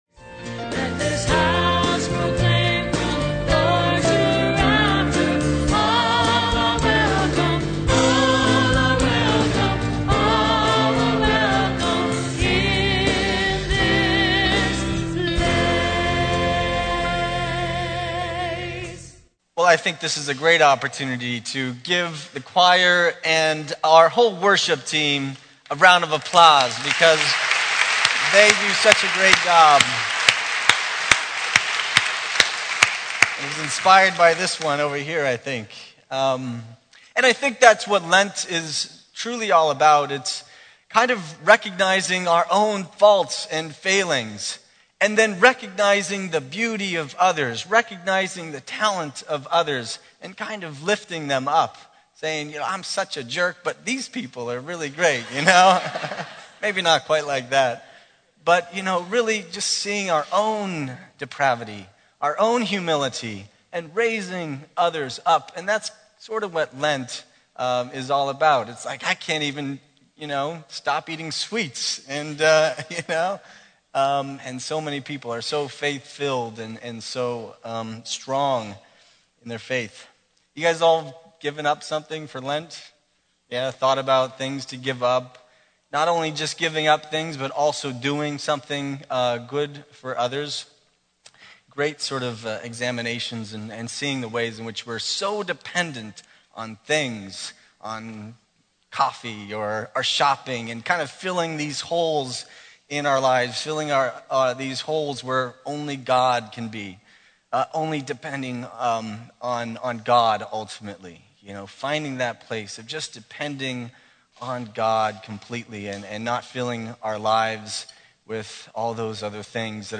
Homily - 2/26/12 - 1st Sunday of Lent - St Monica Catholic Community Media Center